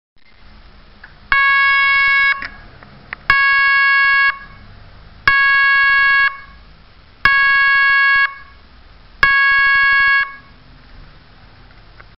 Serie: SIRENAS ELECTRÓNICAS
5 Sonidos independientes seleccionables
Gran rendimiento acústico - 110DB
Tono_2